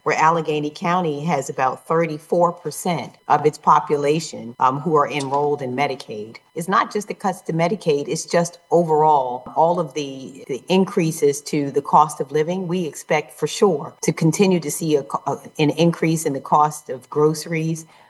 A virtual press conference on Tuesday revealed concerns that Maryland Senators Chris Van Hollen and Angela Alsobrooks have about President Trump’s spending bill. Specifically, cuts to Medicaid that would affect over a million Maryland residents. WCBC asked about specific impacts to Allegany County that Senator Alsobrooks addressed, saying deep Medicaid cuts will be on top of other expenses…